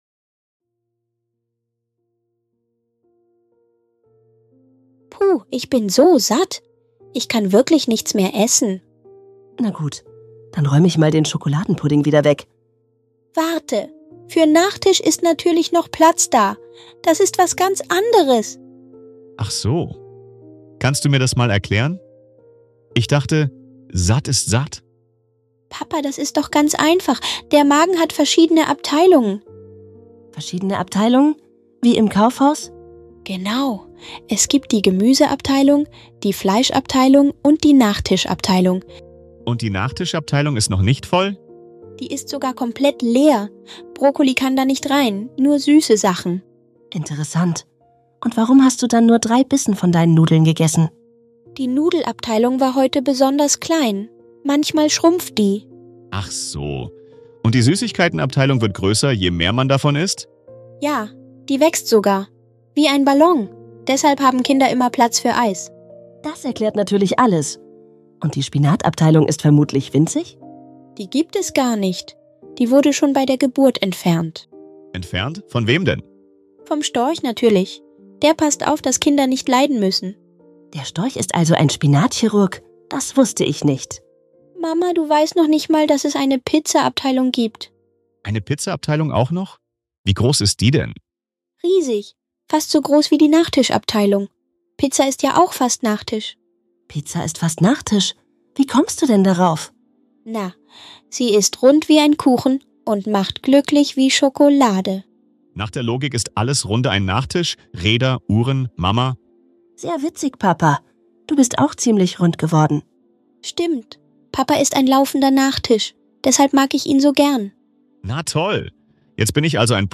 In diesem lustigen Gespräch erklärt ein cleveres Kind seinen